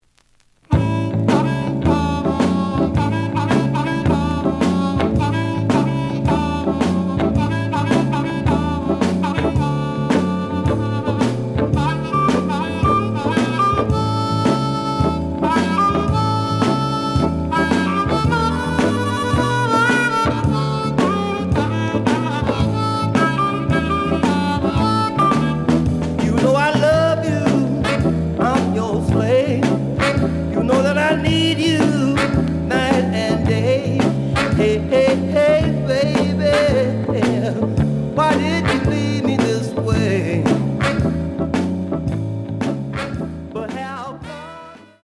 The audio sample is recorded from the actual item.
●Genre: Blues